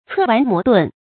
策頑磨鈍 注音： ㄘㄜˋ ㄨㄢˊ ㄇㄛˊ ㄉㄨㄣˋ 讀音讀法： 意思解釋： 鞭策愚頑，磨礪遲鈍。極言勉力奮進。